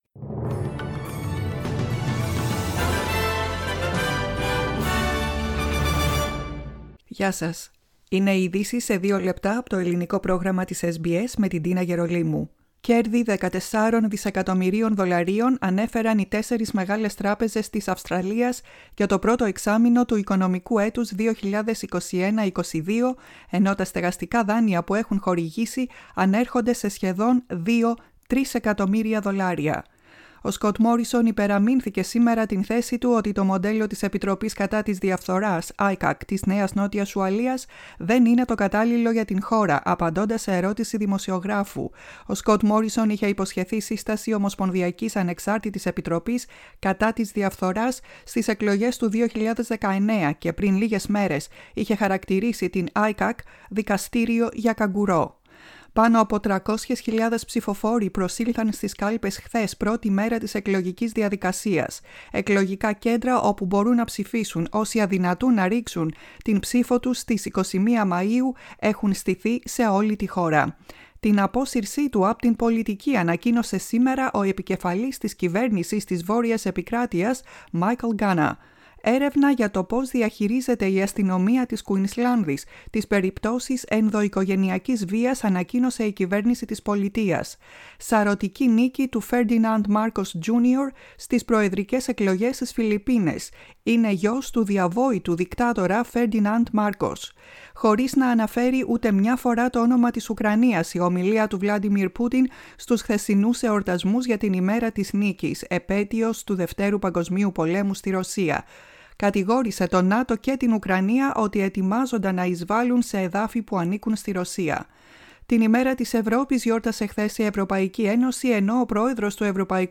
Greek News Flash.